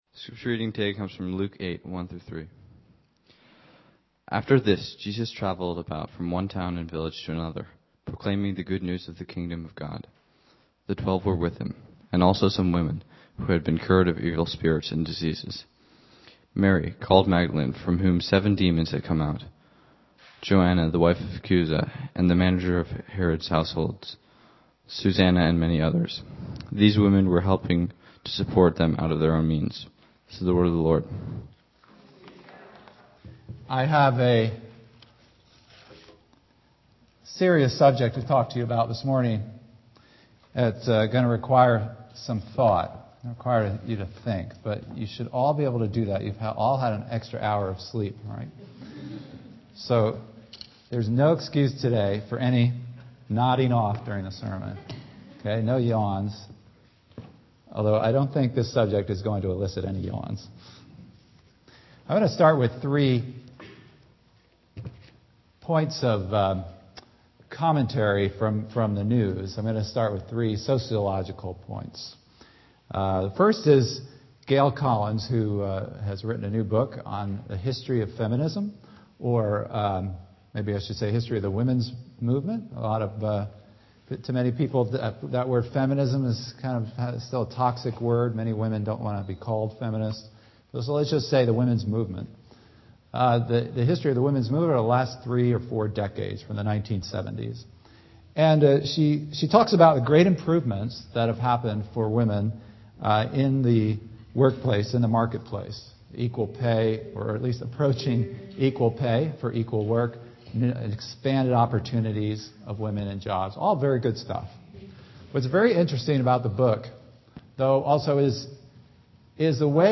In this sermon I also make shocking confession regarding myself and alcohol.